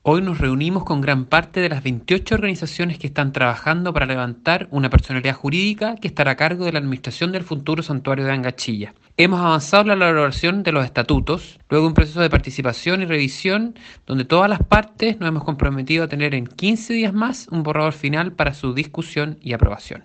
El seremi del Medio Ambiente de Los Ríos, Daniel del Campo, dijo a La Radio que respecto del Angachilla hay 28 organizaciones, entre juntas de vecinos, clubes deportivos, fundaciones, entre otras, las que participan del proceso para generar la corporación que administrará el futuro santuario.